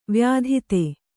♪ vyādhite